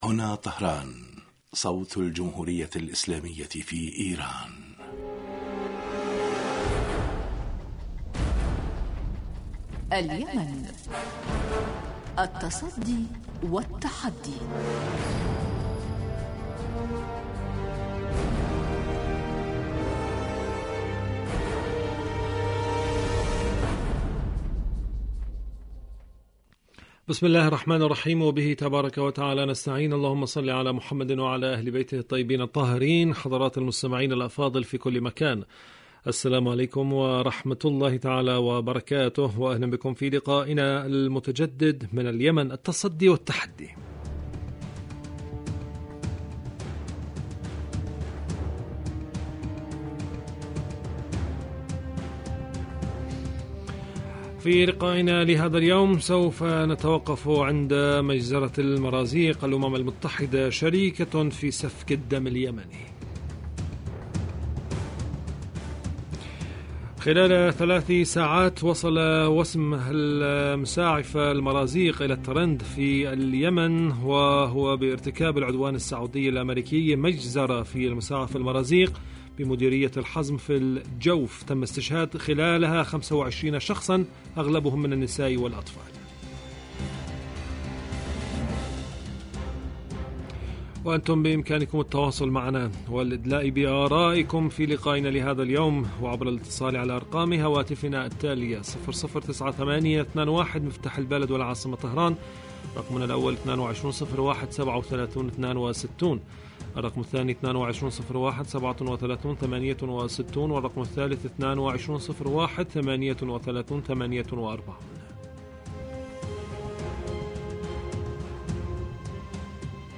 برنامج سياسي حواري يأتيكم مساء كل يوم من إذاعة طهران صوت الجمهورية الإسلامية في ايران .
البرنامج يتناول بالدراسة والتحليل آخر مستجدات العدوان السعودي الأمريكي على الشعب اليمني بحضور محللين و باحثين في الاستوديو